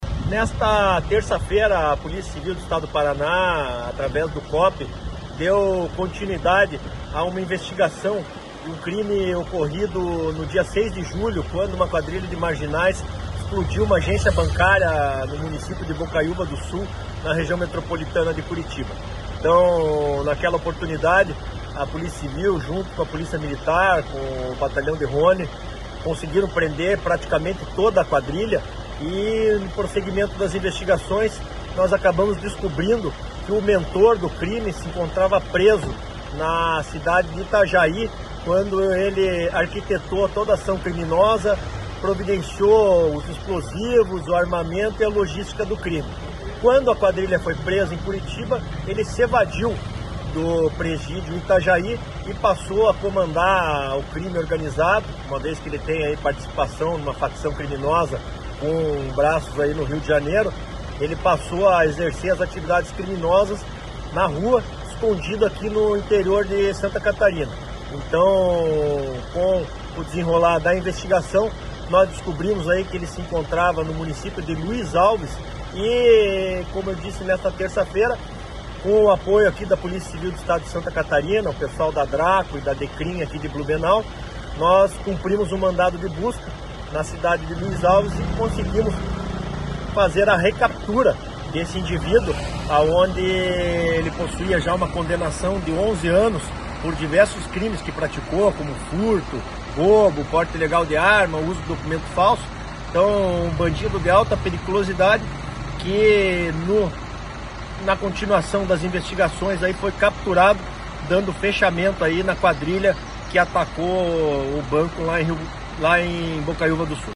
Sonora do delegado da Polícia Civil